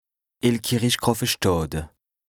Français Dialectes du Bas-Rhin Dialectes du Haut-Rhin Page